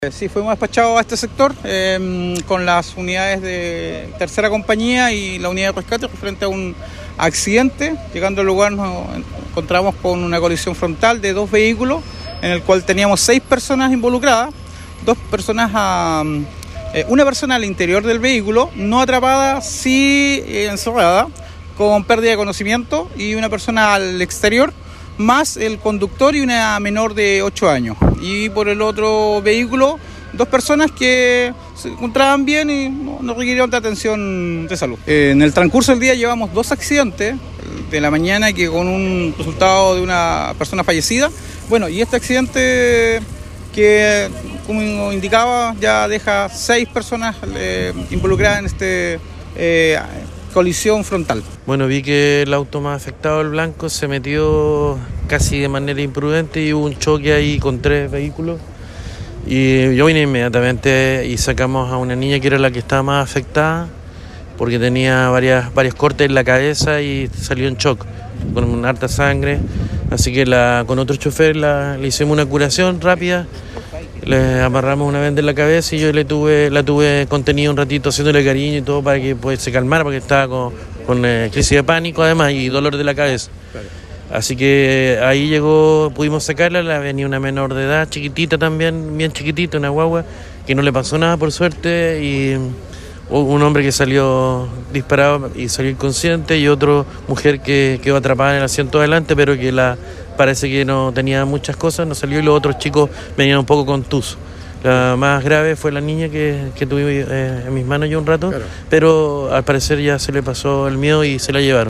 un testigo de este choque